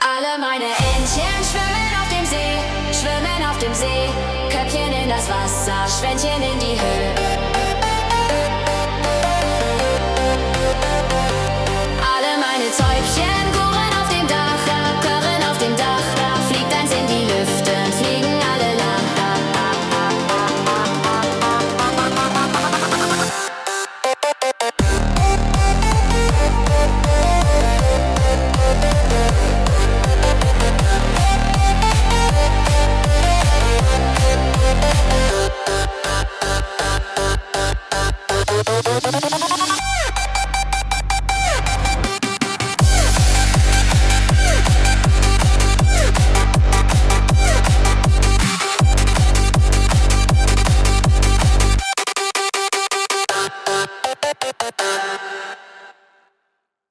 Alle meine Entchen – electronic dance [EDM, synthesizers, energetic beat drop, electronic dance music, uplifting, party vibes, catchy]
Alle-meine-Entchen-elektronik-dance.mp3